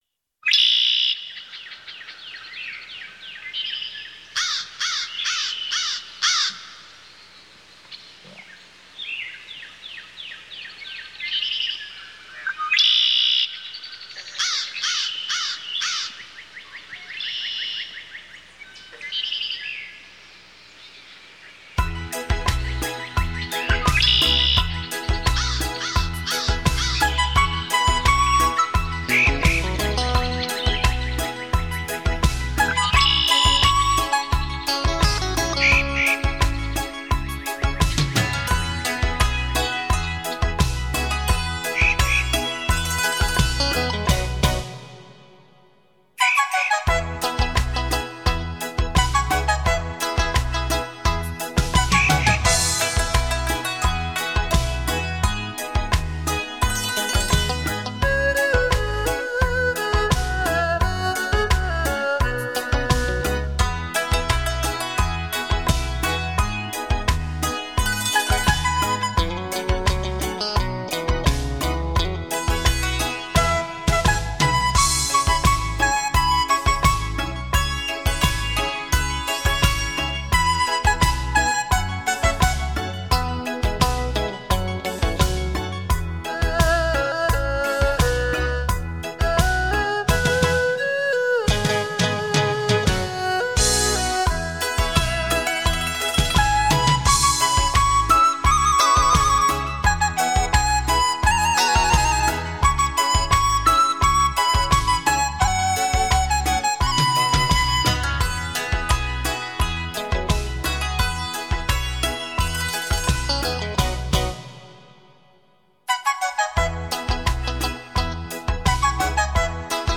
15首福建流行金曲
华乐